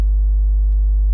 sin220v.wav